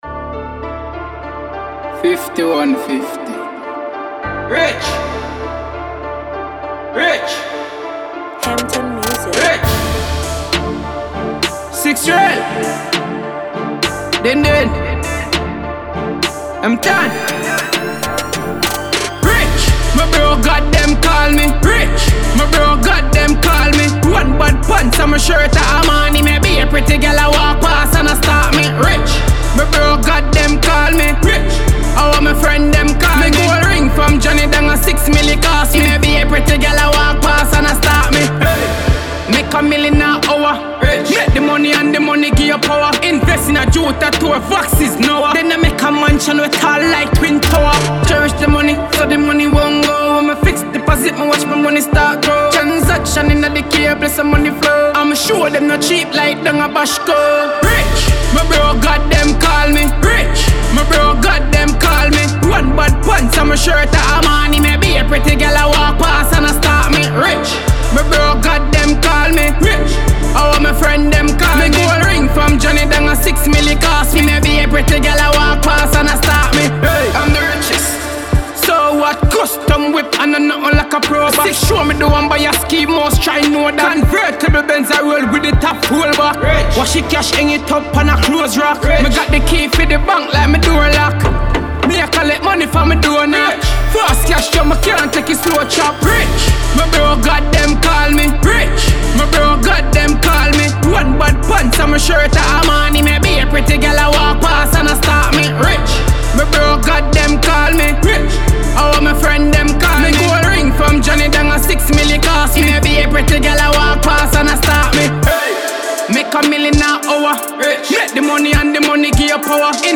dancehall jamaïcain
le chanteur de dancehall